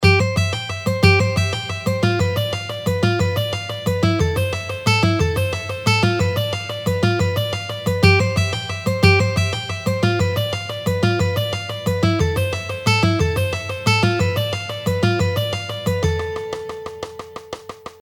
Now lets do in 3 string:
Sweep-Picking-Exercise-2.mp3